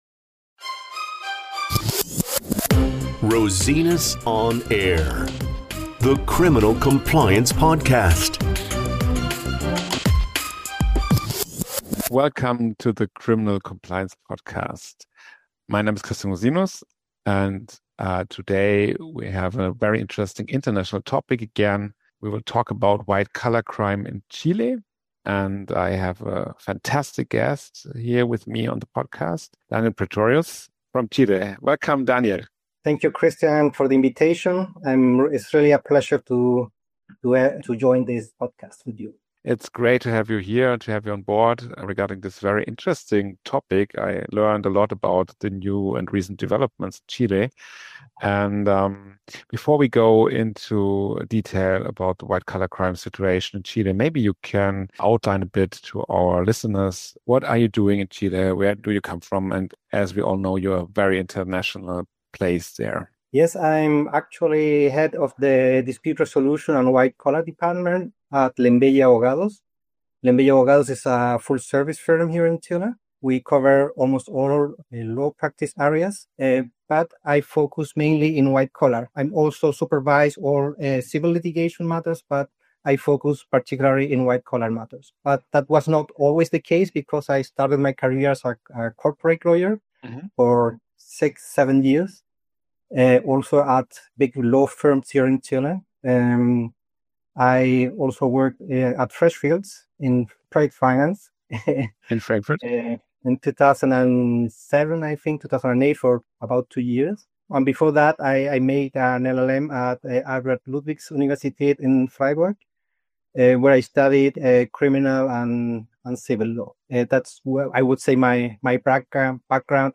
White Collar Crime in Chile - Interview